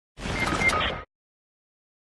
Play, download and share BIBUBIBU! original sound button!!!!
fortnite-death-sound-effect-hd-320-kbps-1.mp3